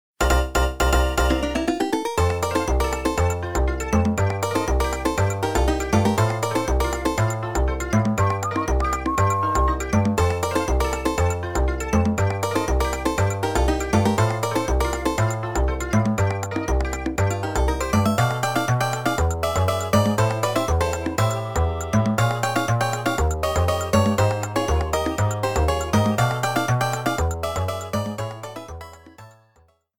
Background music
Reduced length to 30 seconds, with fadeout.